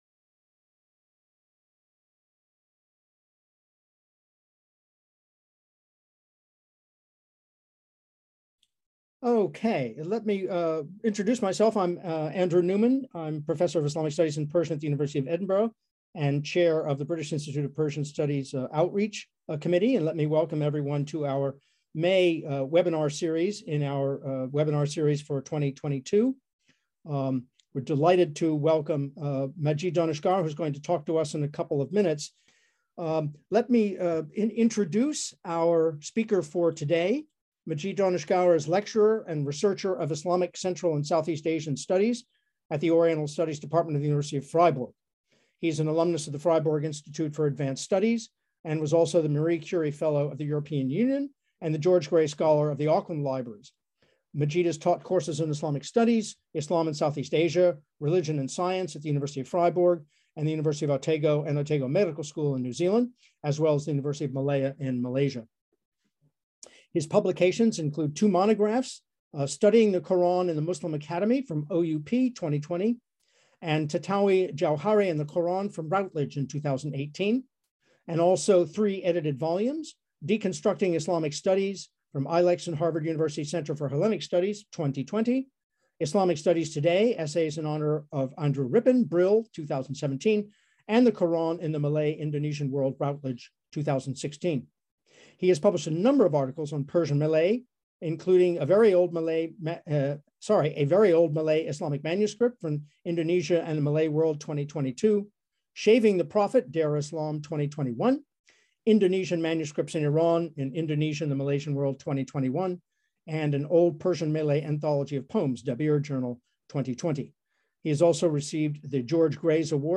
Ann Lambton Memorial Lecture: Unequal Treaties and the Question of Sovereignty in Qajar and early Pahlavi Iran